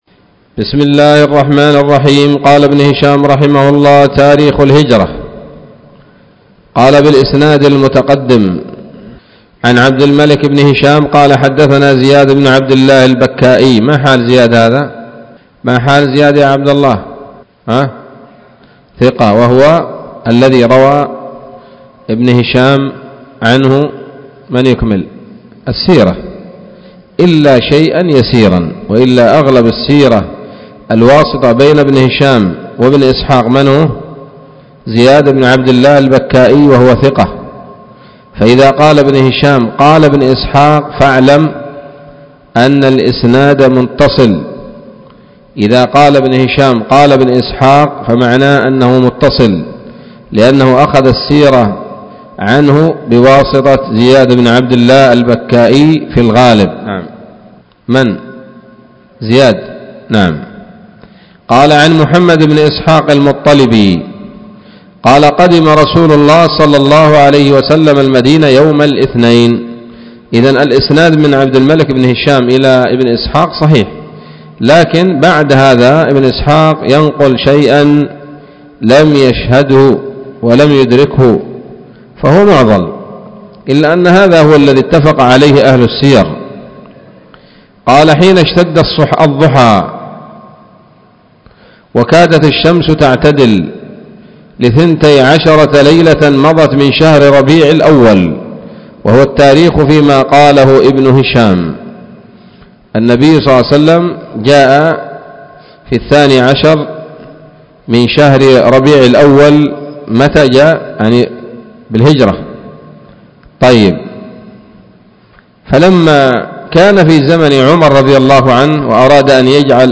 الدرس الثالث بعد المائة من التعليق على كتاب السيرة النبوية لابن هشام